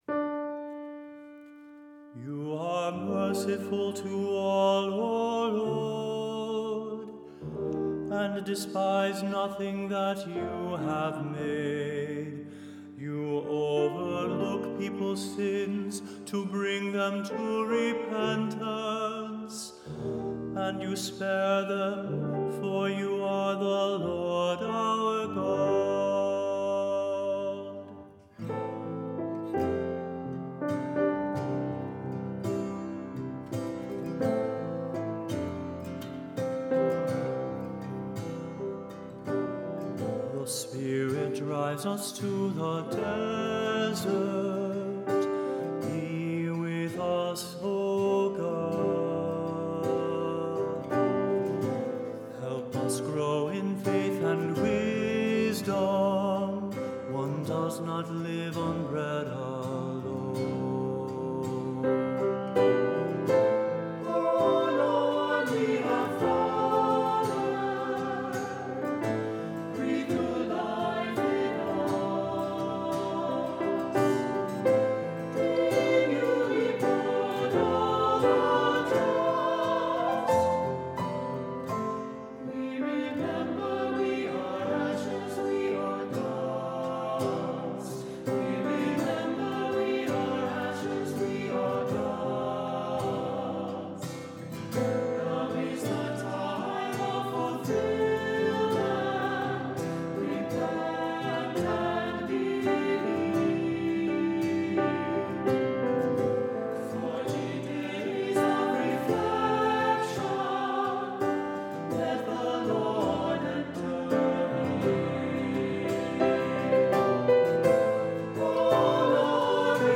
Voicing: "Two-part mixed","Cantor","Assembly"